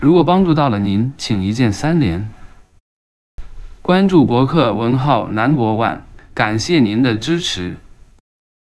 本教程介绍如何使用 Coqui TTSXTTS v2 模型 实现中文语音克隆，支持直接传入 .wav 文件，还原你的音色与语调，生成带有个性化音色的语音合成结果。
🚀 方案一：快速语音克隆（需标准音频）
克隆output.wav